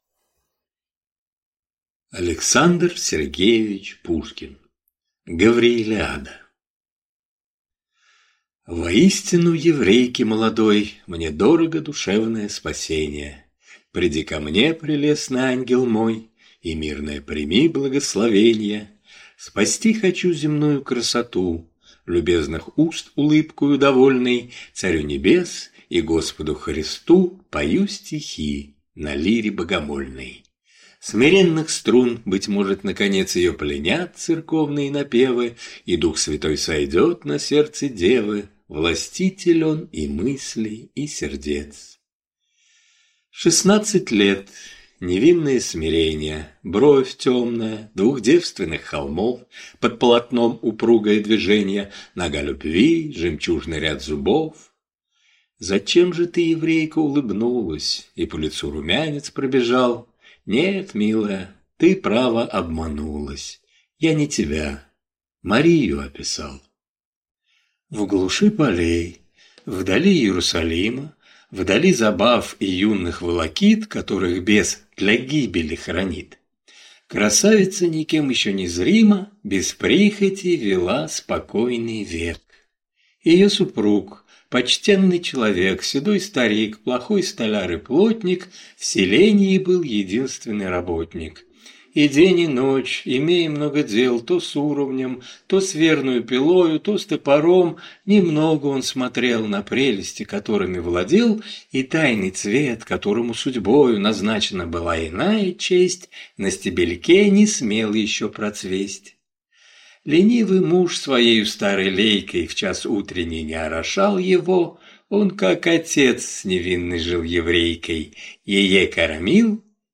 Аудиокнига Гавриилиада | Библиотека аудиокниг